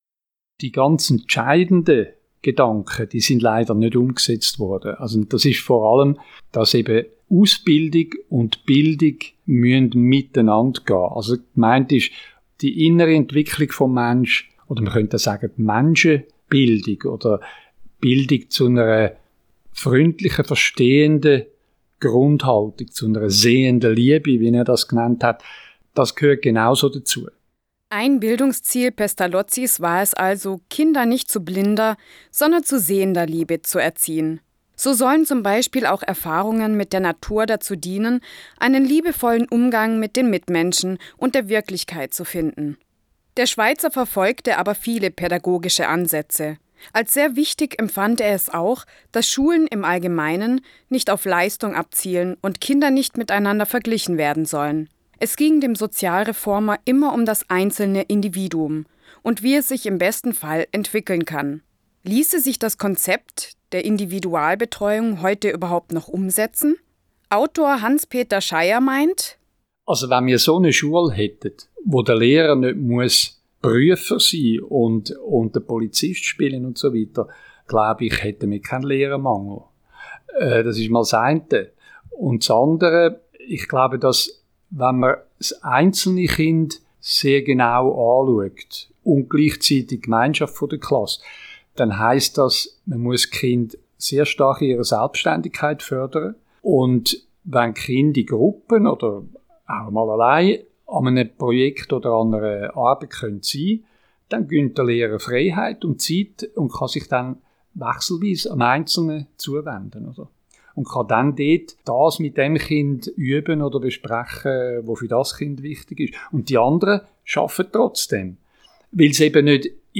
Interview Radio Rasa